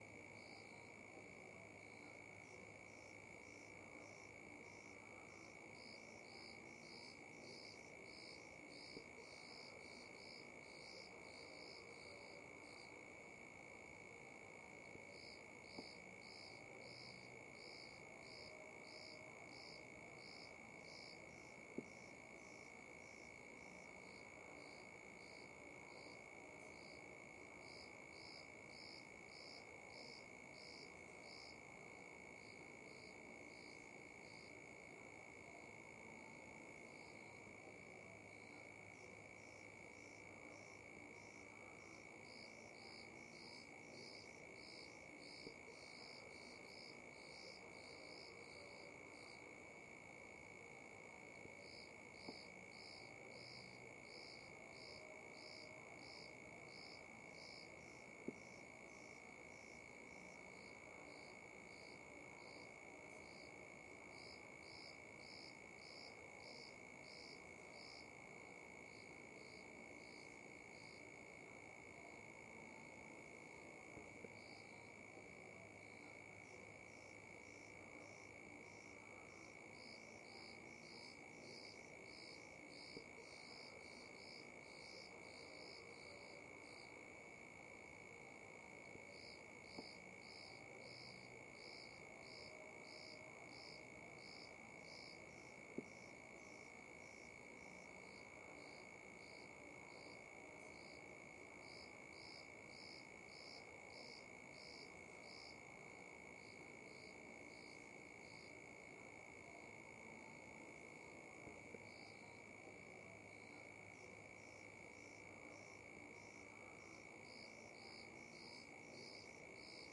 声音效果 " 后院氛围之夜
描述：后院的声音在晚上
Tag: 蟋蟀 后院 气氛